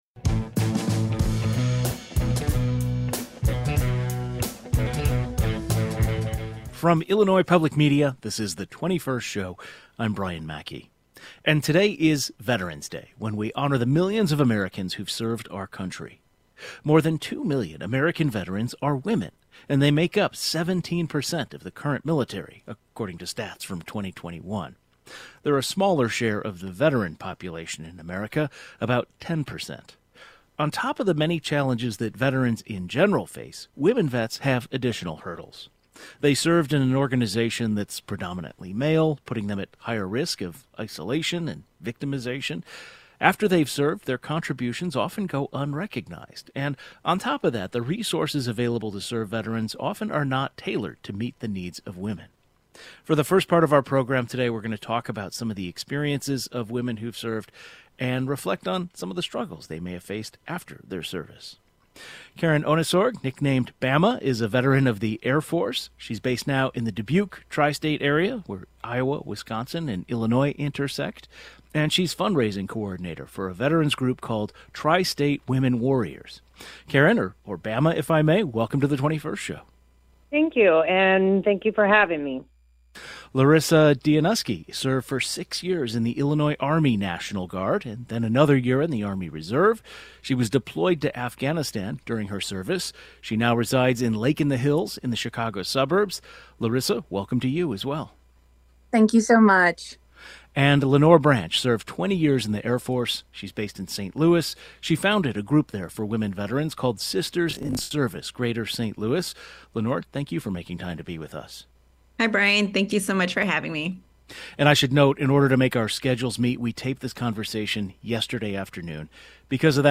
To mark Veterans Day, Illinois women who’ve served in the military speak about some of their experiences and reflect on some of their struggles. The 21st Show is Illinois' statewide weekday public radio talk show, connecting Illinois and bringing you the news, culture, and stories that matter to the 21st state.